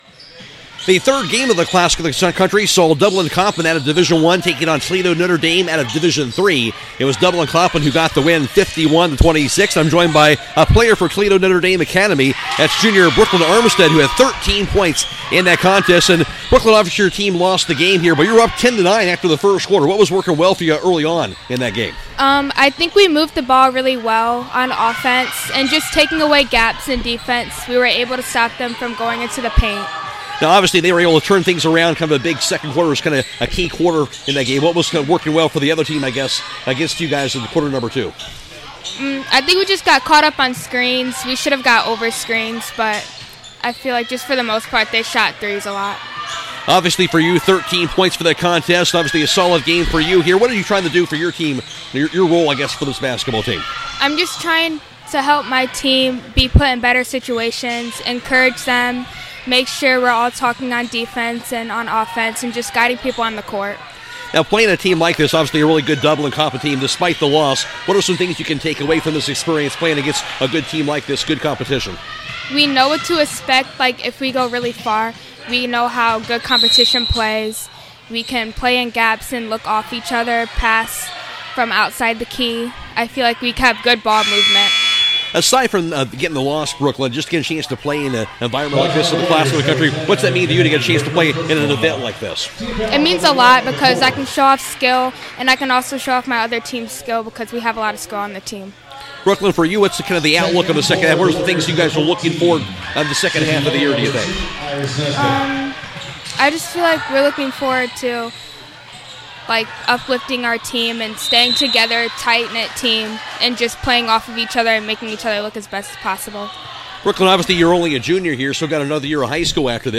2026 CLASSIC – NOTRE DAME PLAYER INTERVIEWS